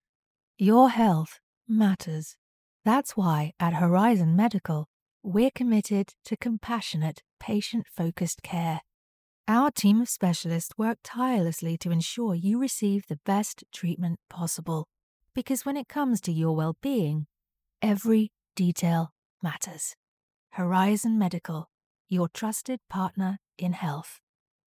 Female
Studio Quality Sample
British I Horizon Medical
Words that describe my voice are Conversational, Believable, Engaging.